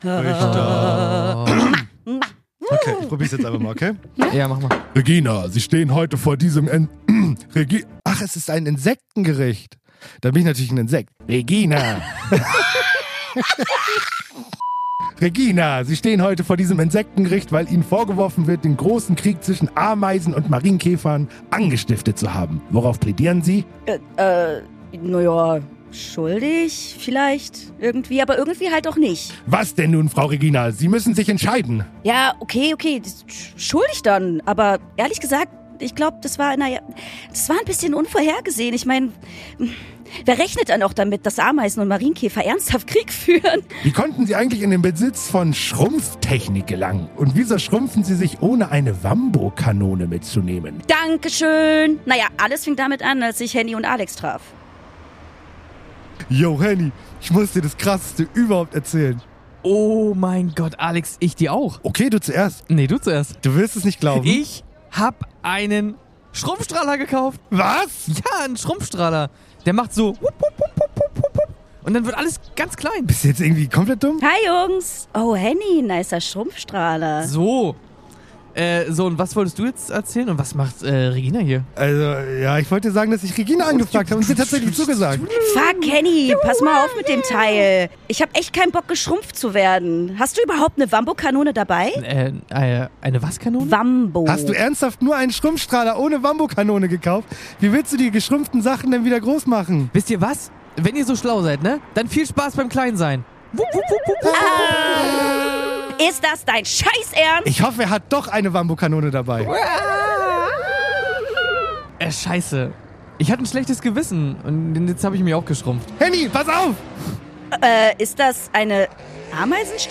Wir kriegen ein Wort und müssen so schnell wie es geht einen Song singen, in dem es vorkommt, Blackouts garantiert!